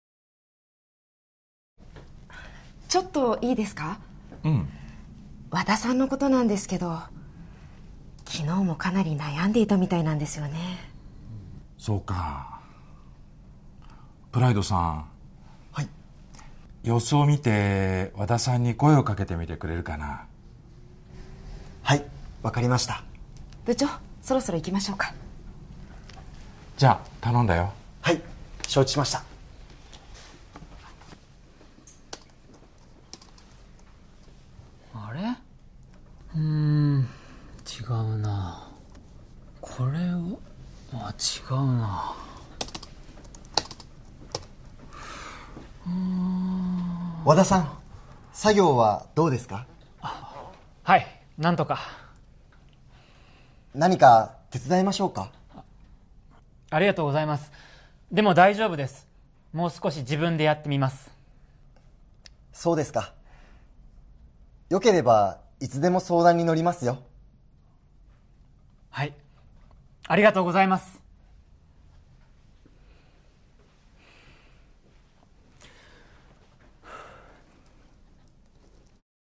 Conversation Transcript
skit17.mp3